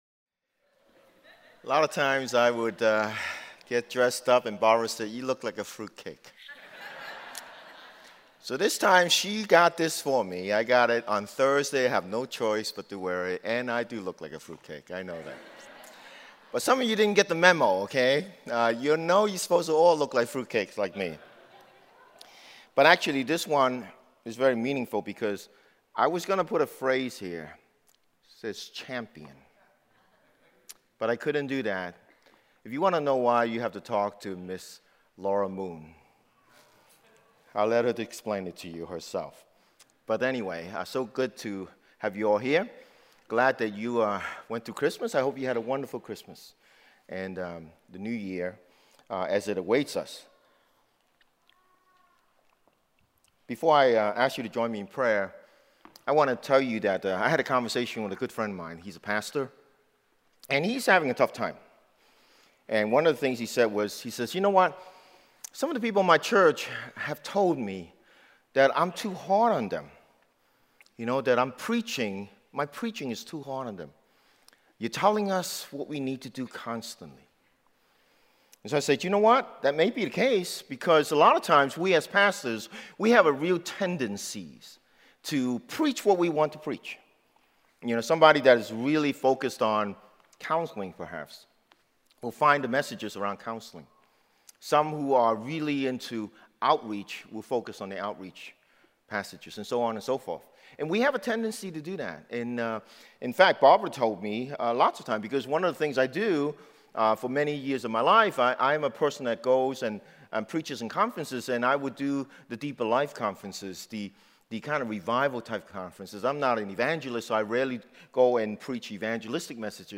2018 English Sermons